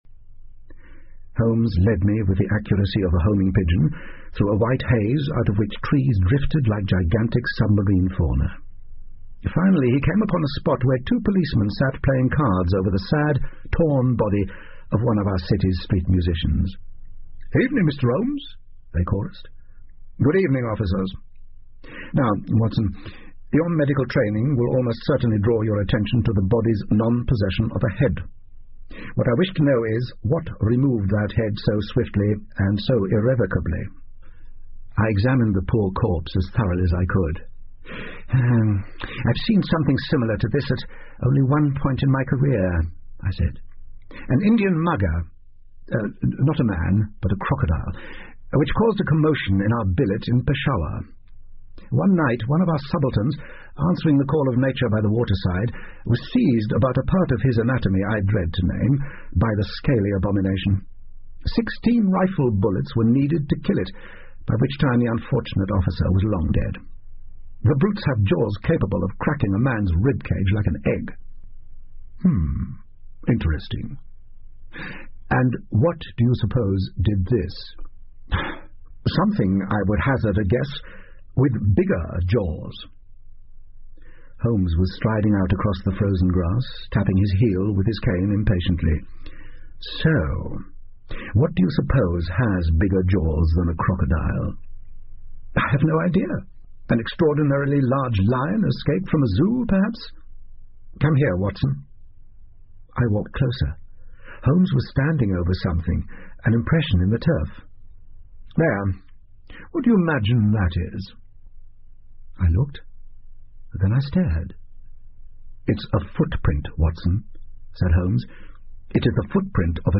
福尔摩斯广播剧 Cult-The Lost World 2 听力文件下载—在线英语听力室